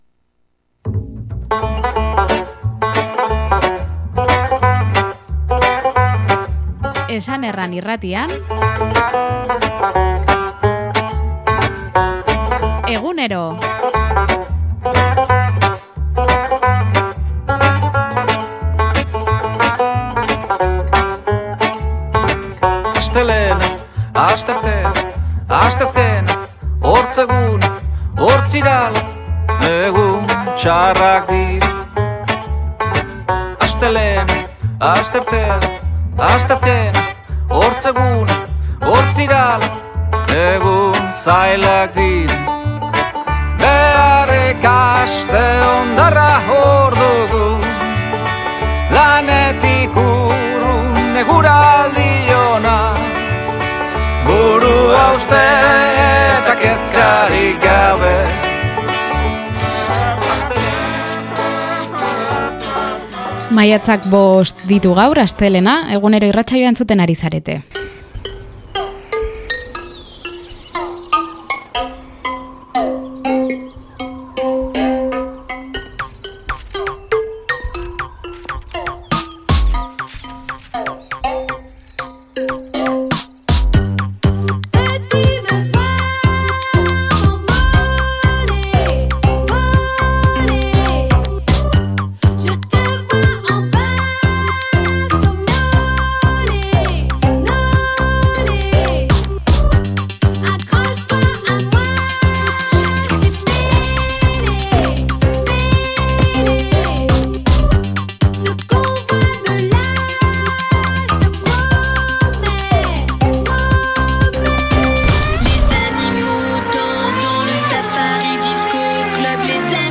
eskualdeko magazina ESAN ERRAN IRRATIA